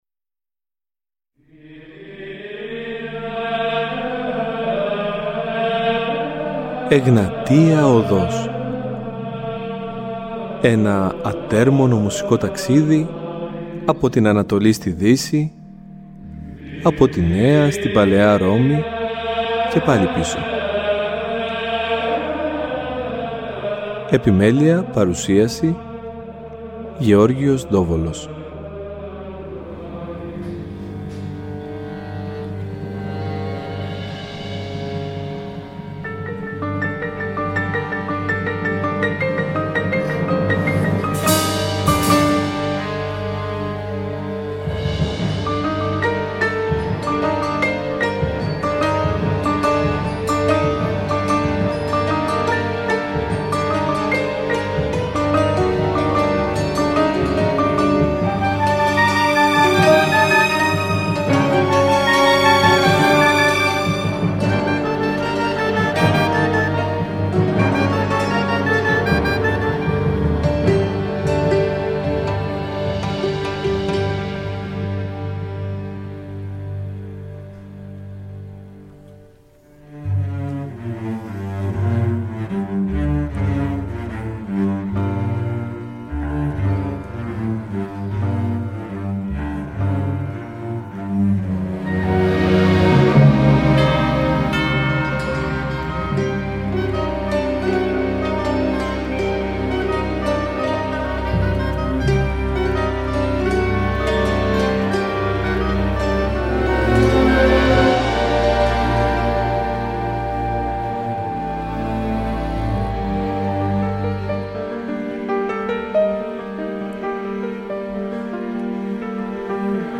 Βυζαντινη Μουσικη
Εκκλησιαστικη Μουσικη